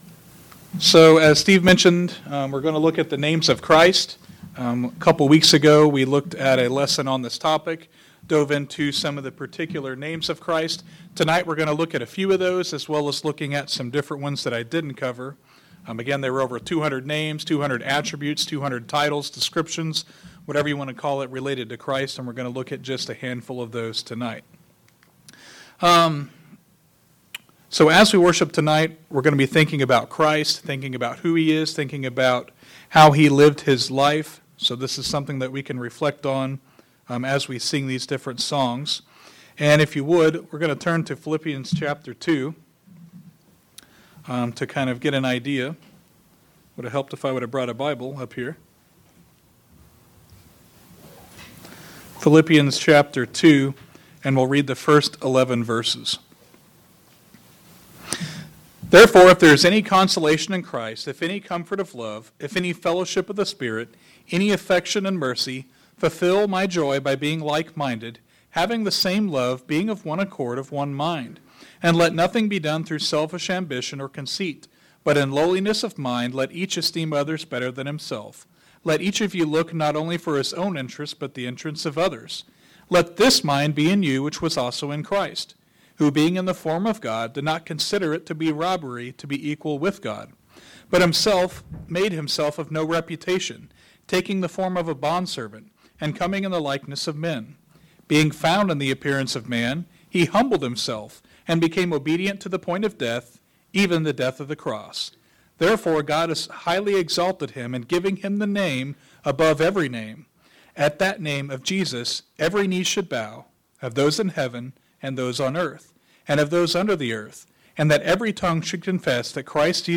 Names of Christ Song Service